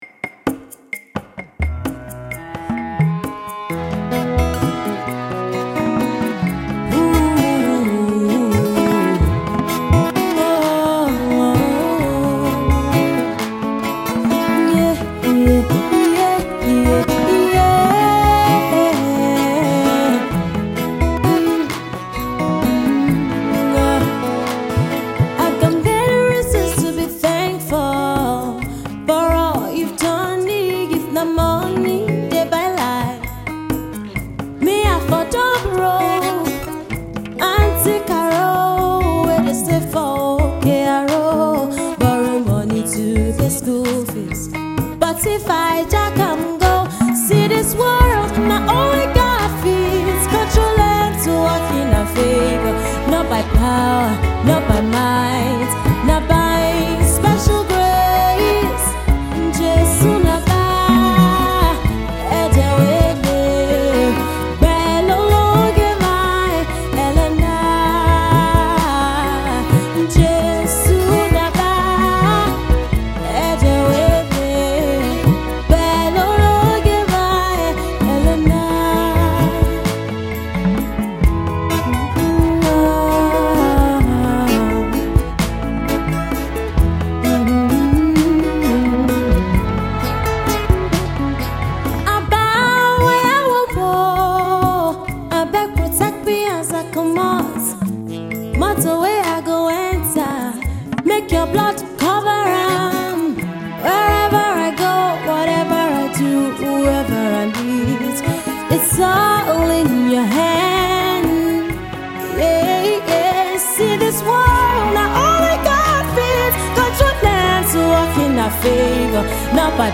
A classic that ushers you into the depth of gospel soul.